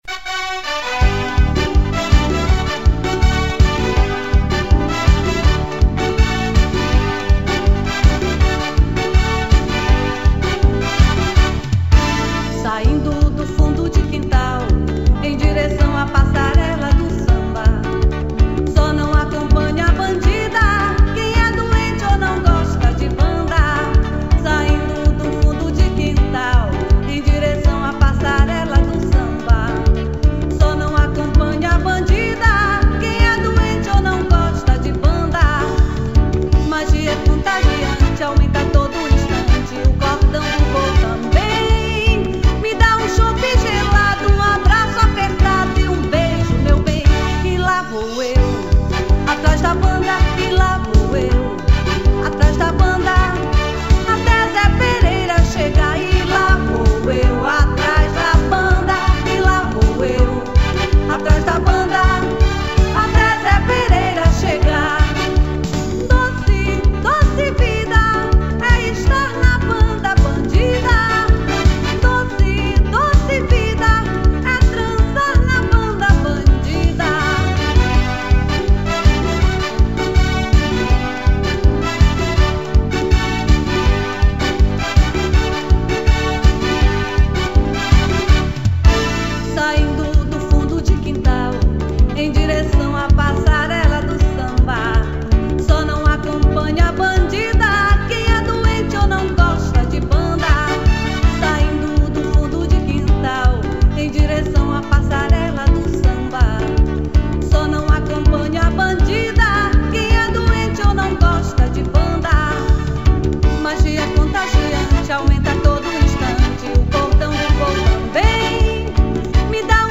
2469   03:20:00   Faixa:     Machinha